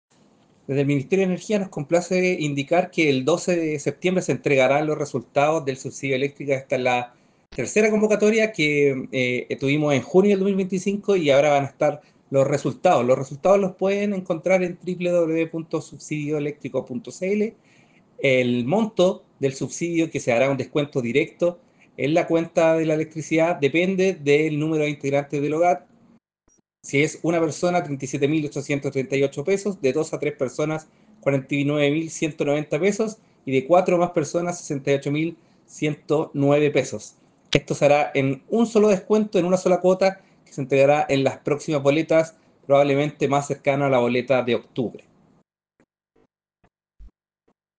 El Seremi de Energía, Claudio Martinez hizo el llamado para que usuarios verifican la información.